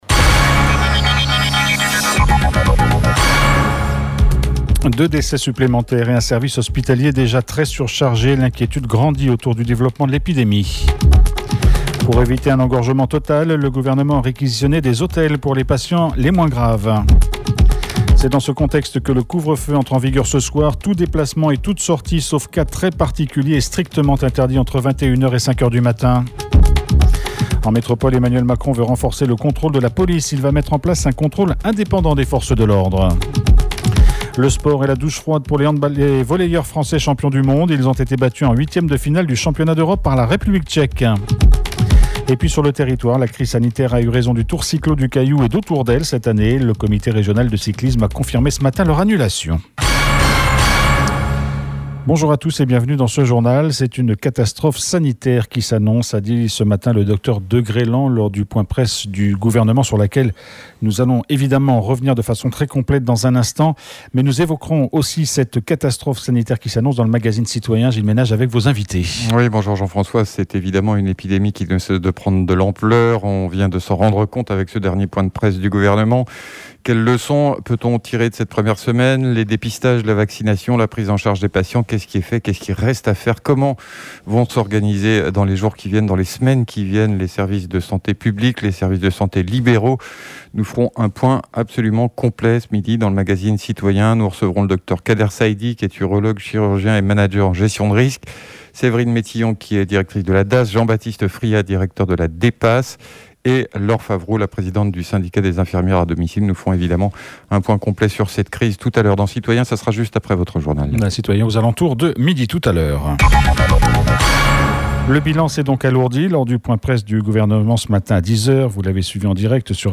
JOURNAL : MARDI 14/09/21 (MIDI)